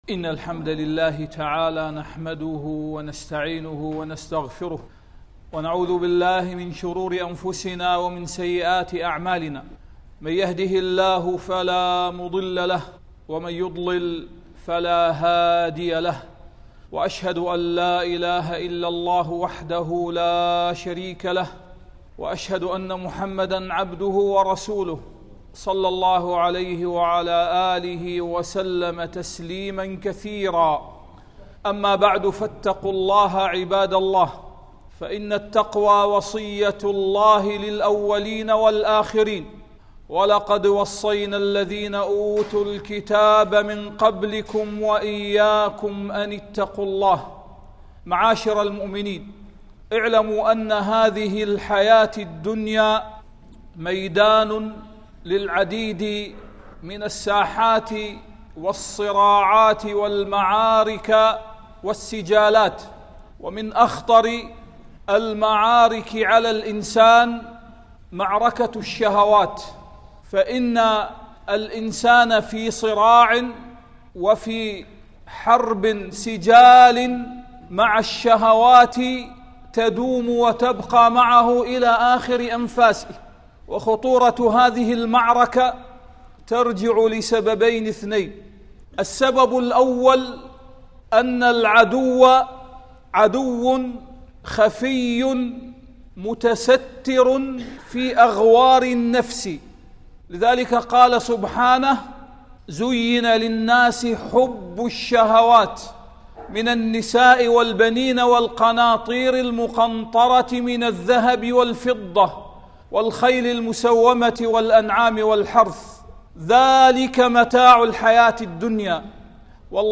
تذكر أيها المذنب: أن لذة قهر الشهوات، أعظم من لذة الشهوات!. التصنيف: خطب الجمعة